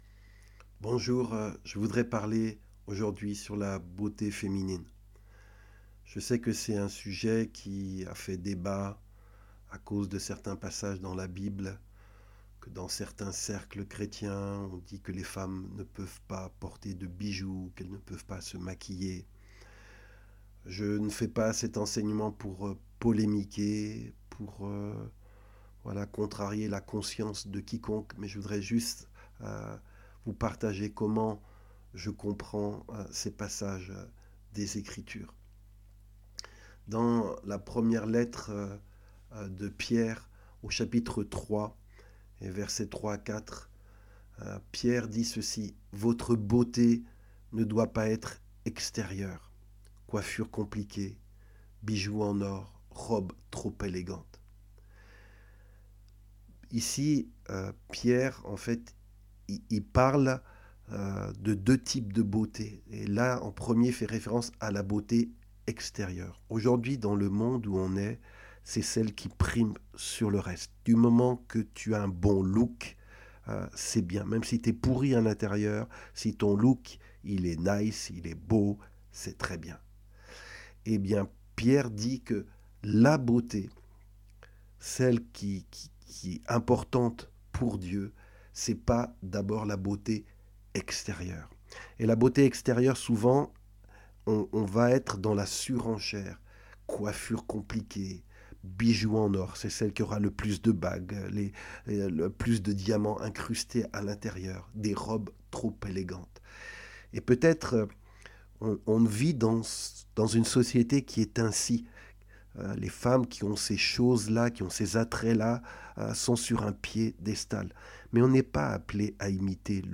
Enseignement biblique : La femme chrétienne et la mode, les habits, les coiffures.